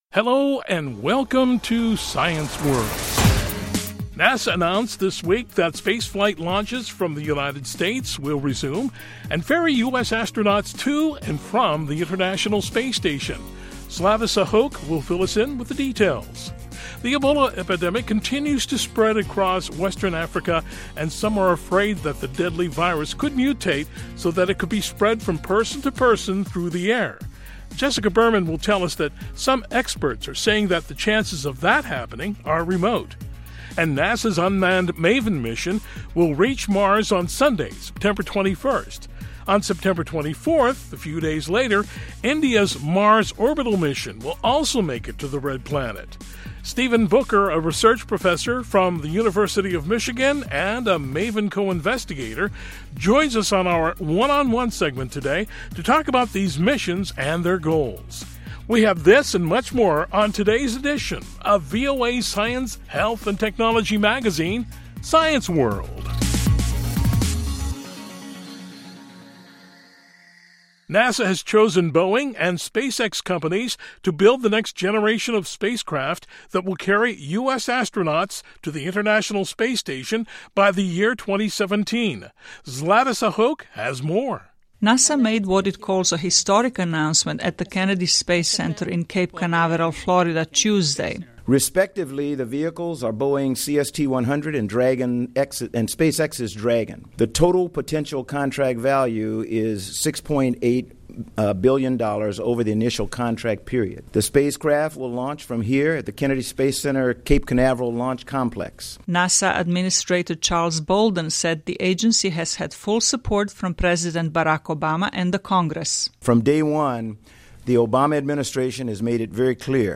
We'll this and much more for you on today's edition of VOA's science, health and technology magazine, "Science World."